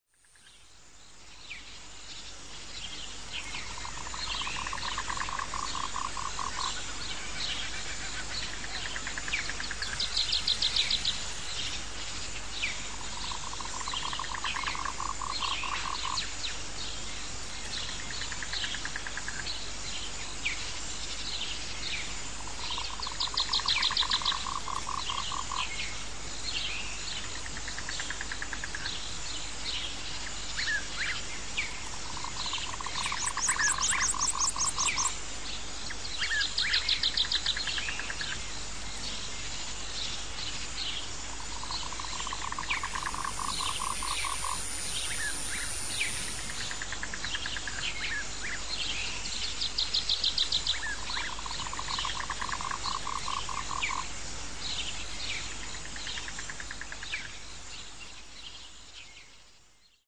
Category: Animals/Nature   Right: Personal